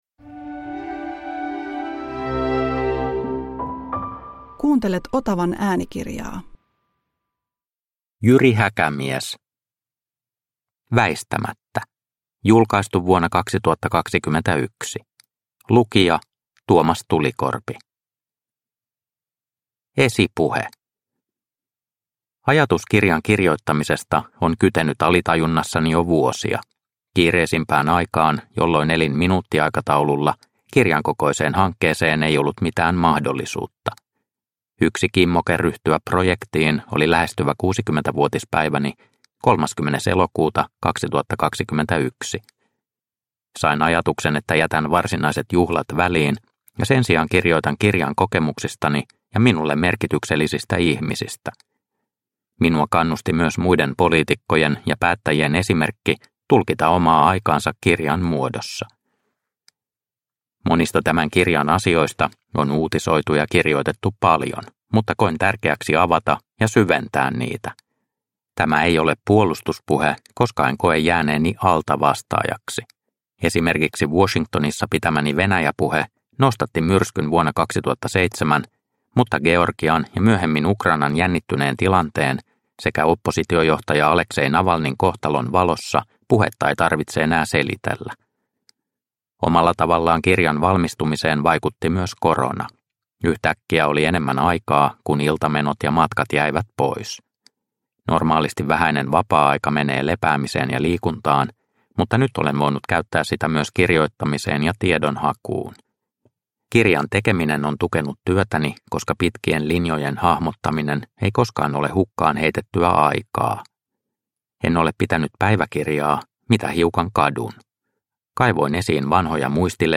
Väistämättä – Ljudbok – Laddas ner